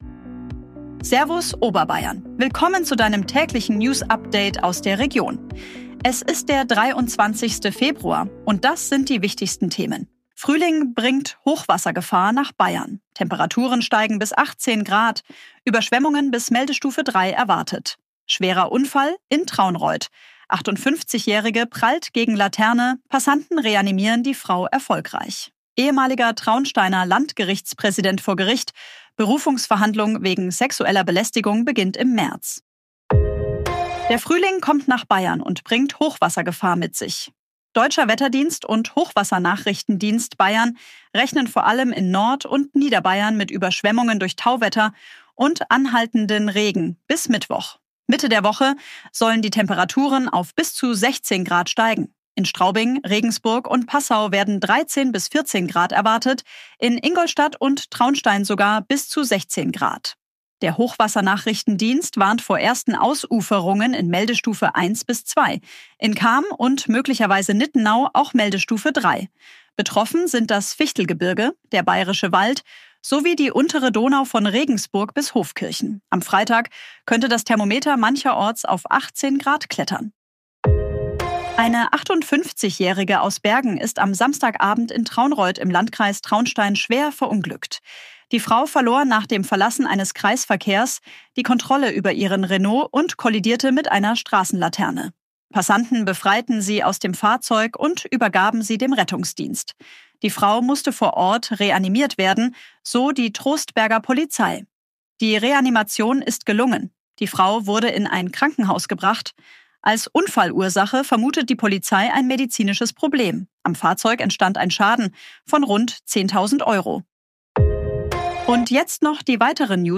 Tägliche Nachrichten aus deiner Region
künstlicher Intelligenz auf Basis von redaktionellen Texten